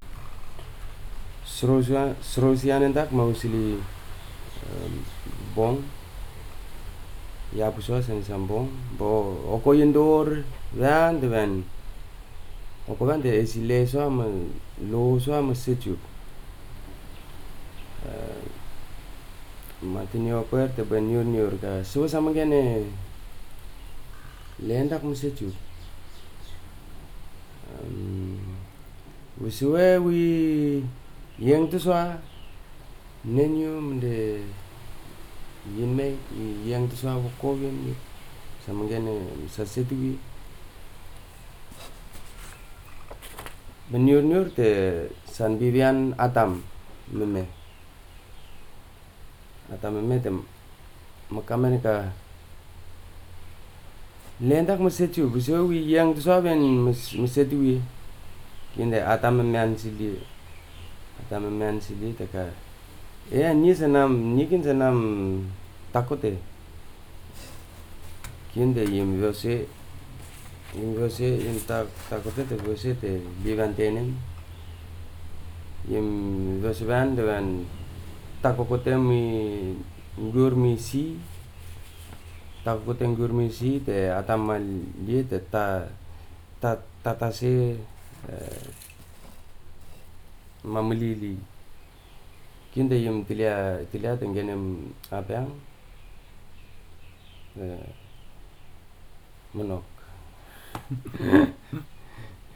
digital wav file recorded at 44.1 kHz/16 bit on Marantz PMD 620 recorder
Port Vila, Efate, Vanuatu